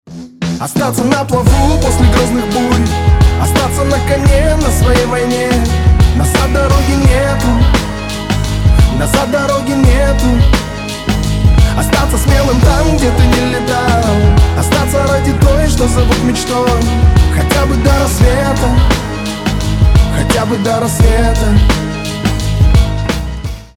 • Качество: 320, Stereo
мужской голос
лирика
душевные